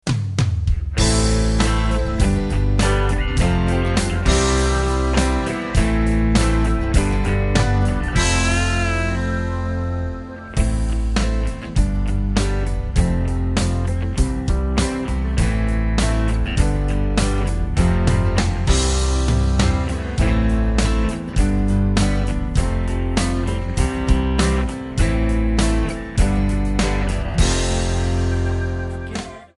Bb
Backing track Karaoke
Pop, 1990s